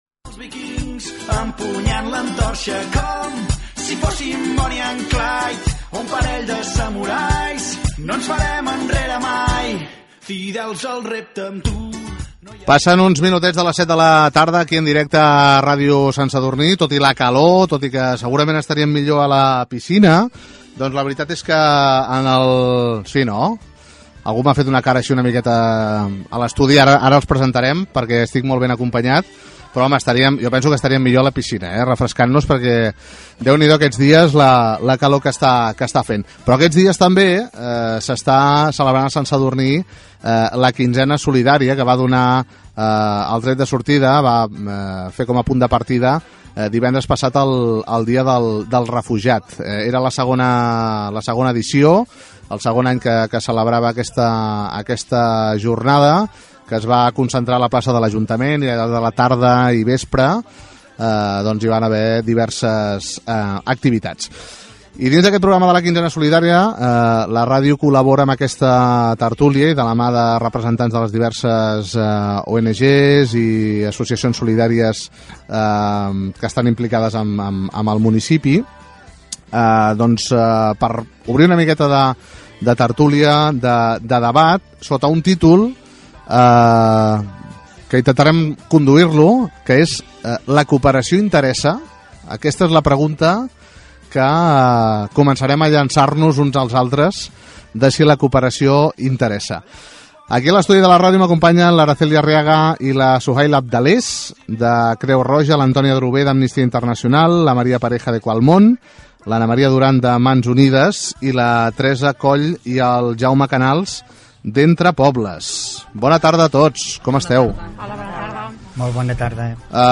Hora, identificació de l'emissora, presentació del programa dedicat a la Quinzena Solidària i el tema si la cooperació internacional interessa. Amb representants de diverses ONG's i el regidor de Cooperació, Jep Bargalló.
Informatiu